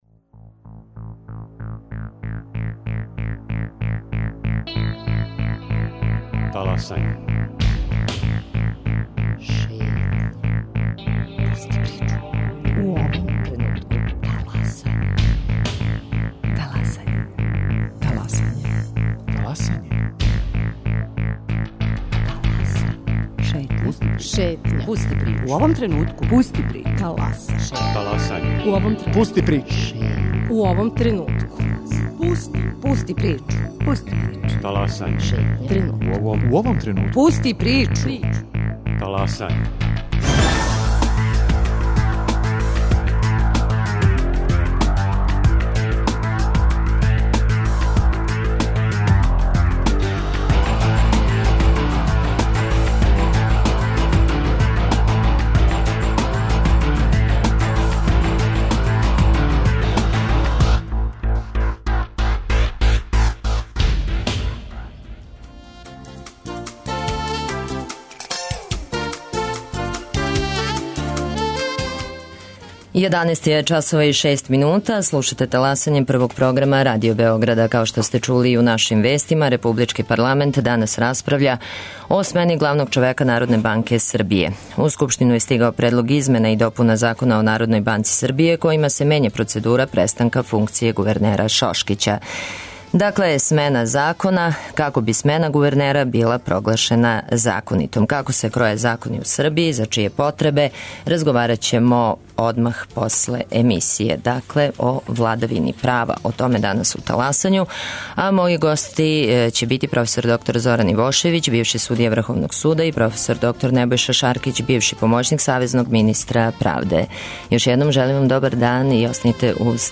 Гости: проф. др Зоран Ивошевић, бивши судија Врховног суда и проф. др Небојша Шаркић, бивши помоћник савезног министра правде.